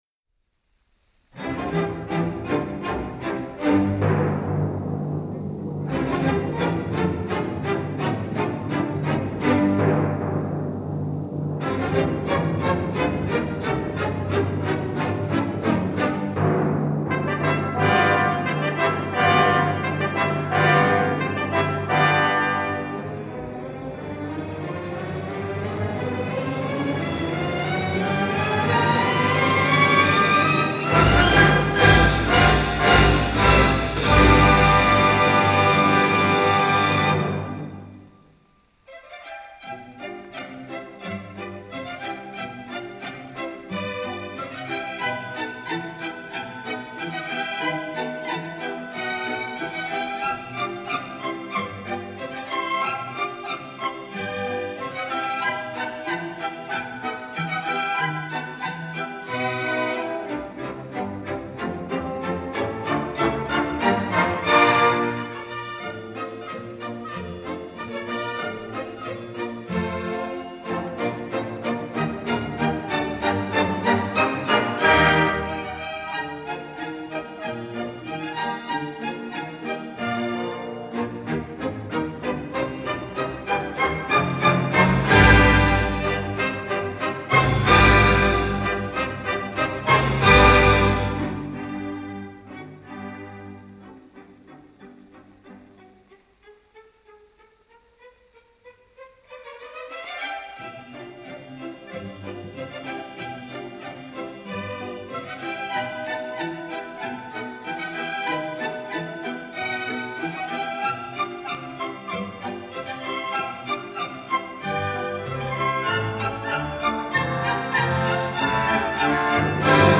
«Проданная невеста». Полька (оркестр п/у Т. Бичема)